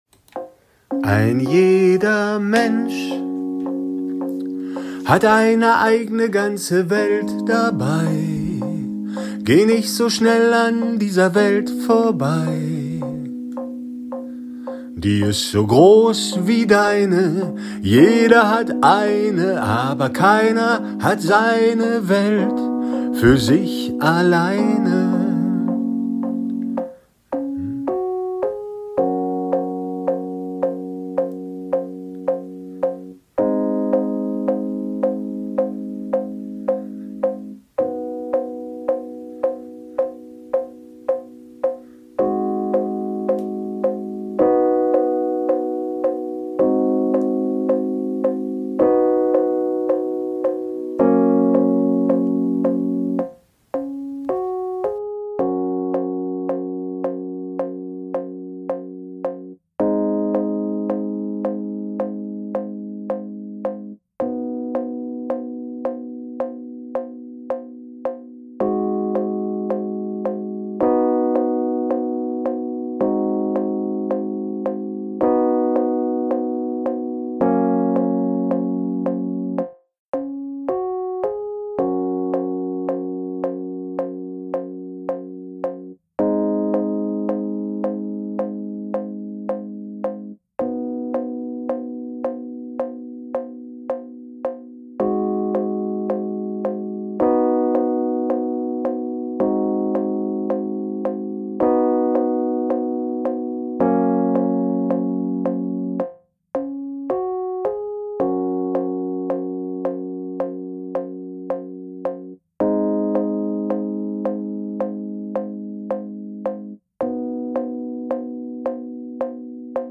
Playback zum Üben